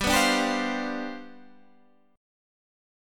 G11 chord